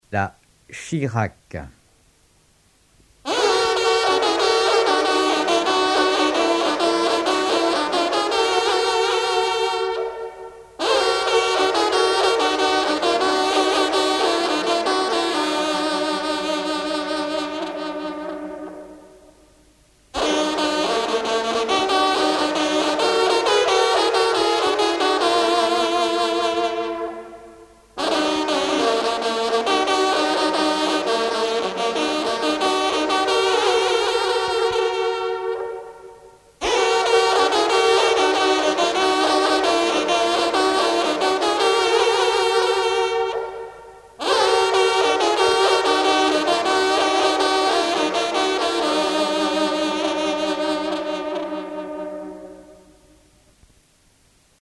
trompe - fanfare - personnalités
circonstance : vénerie